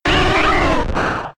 Cri de Goupix K.O. dans Pokémon X et Y.